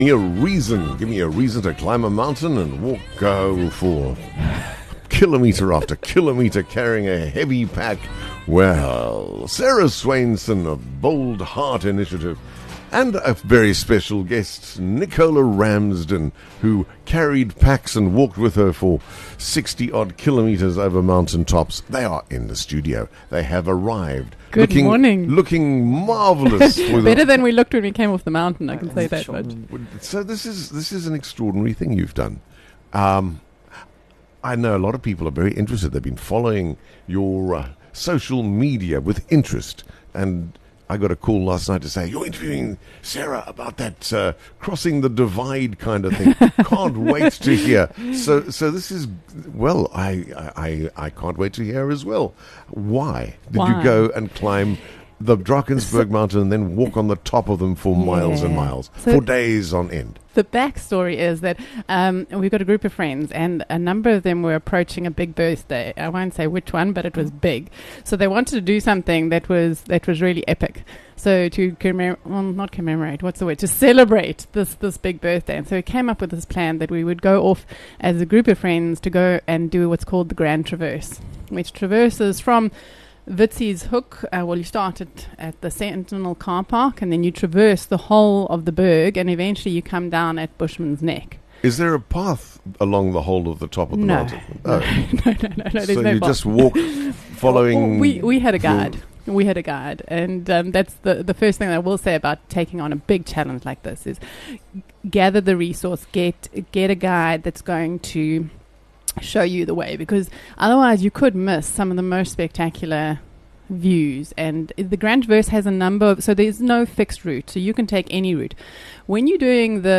Together, they’ll unpack the physical and mental demands of the traverse, how nature changes us, and why the mountains call us to rise higher – not just in altitude, but in life. Expect honest reflections, laughter, and the kind of stories that will stir your soul and maybe even ignite your own next bold move.